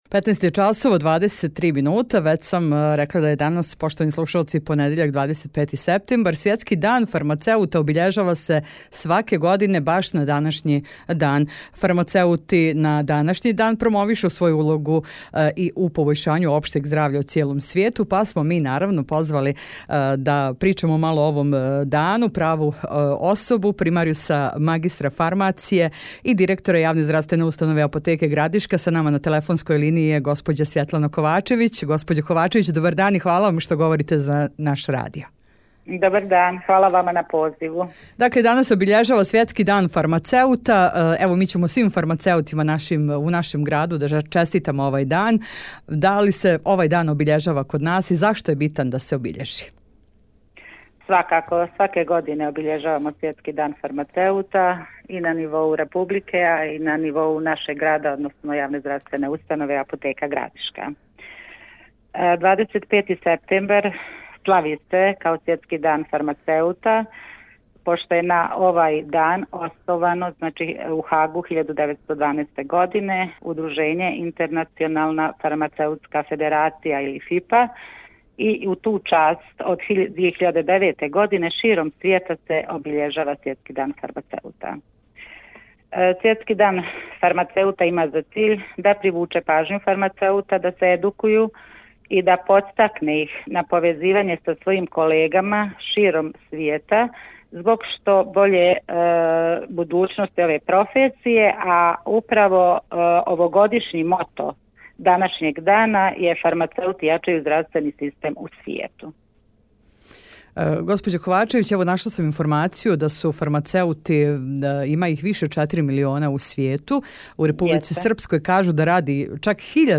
у телефонском разговору за Радио Градишку